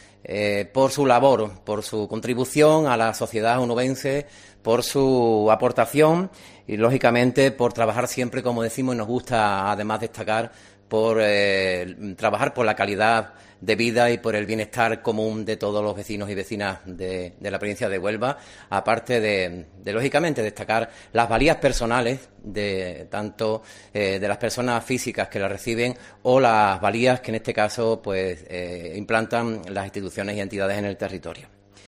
Salvador Gómez, vicepresidente de la Diputación de Huelva